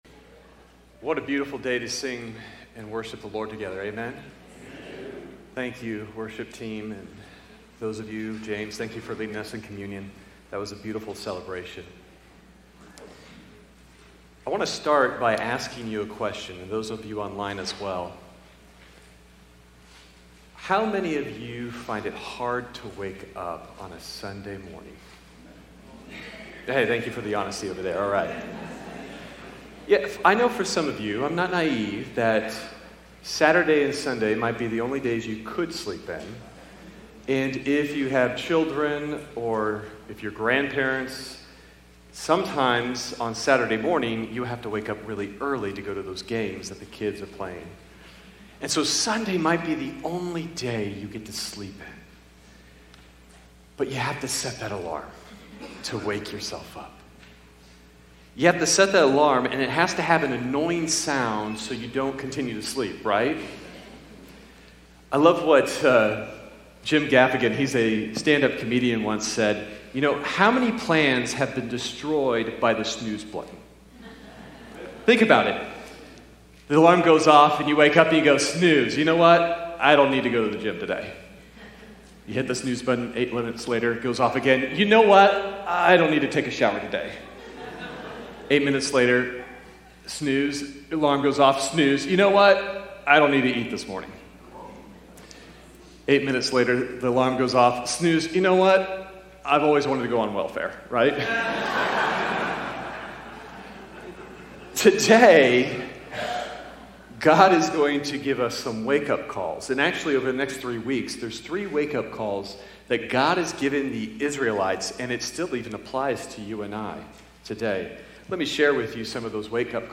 Sermon Detail
The audio kept working, though, so you should be able to hear the whole sermon. 9 Awake, awake, put on strength, O arm of the LORD!
november_12th_worship_service_Sermon_Audio_.mp3